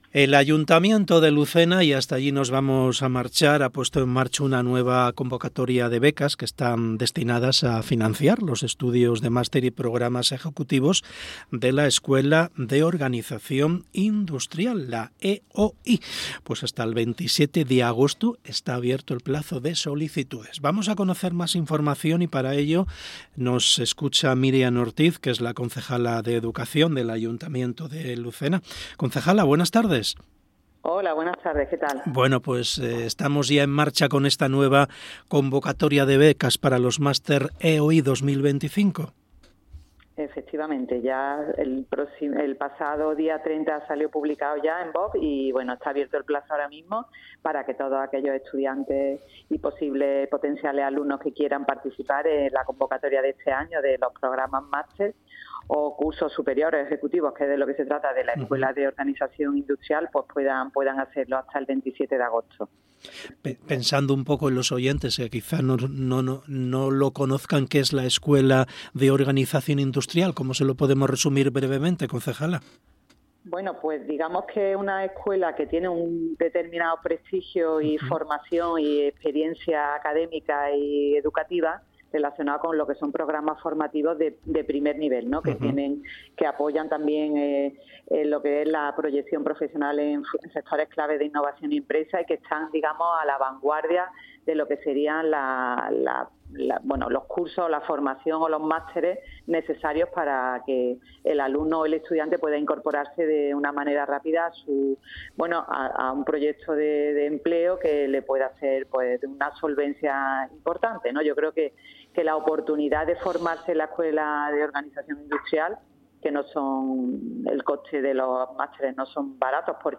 Entrevista Miriam Ortiz Becas EOI Lucena
Miriam Ortiz, concejala de Educación de Lucena, en Hoy por Hoy de verano